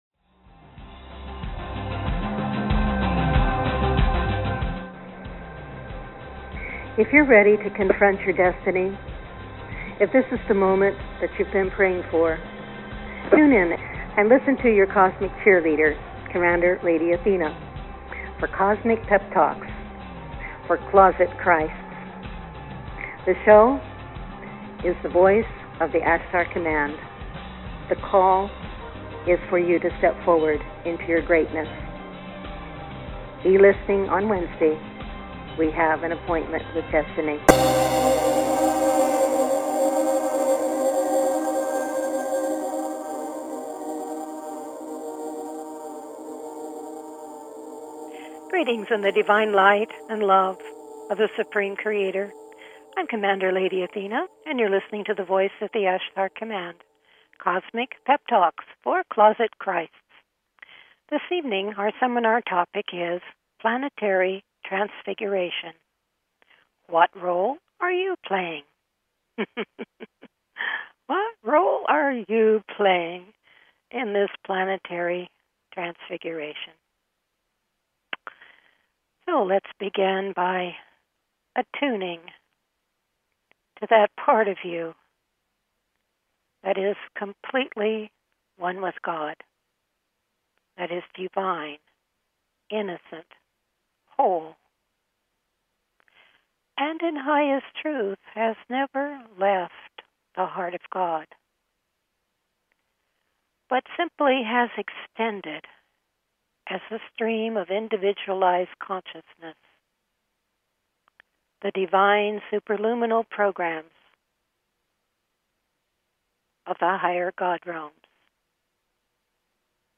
Talk Show Episode, Audio Podcast, The_Voice_of_the_Ashtar_Command and Courtesy of BBS Radio on , show guests , about , categorized as
Various experiential processes, meditations and teachings evoke your Divine knowing and Identity, drawing you into deeper com